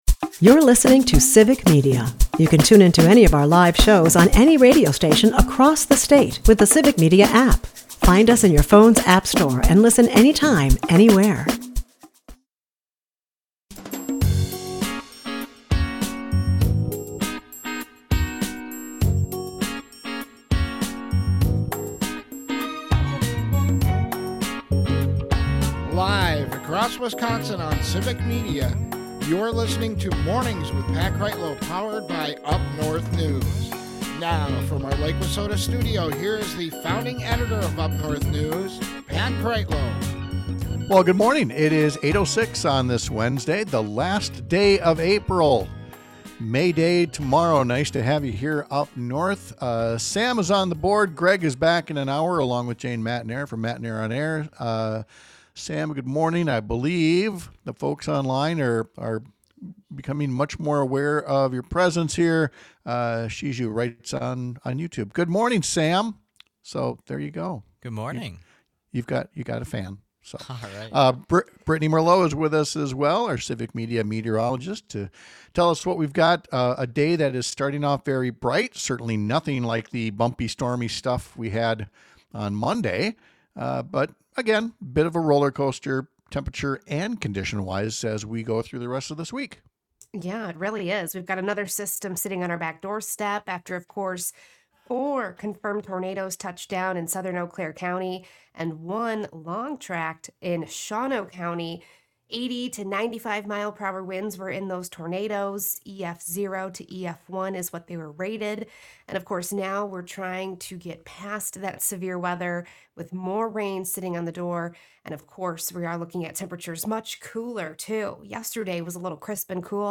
Mornings with Pat Kreitlow airs on several stations across the Civic Media radio network, Monday through Friday from 6-9 am.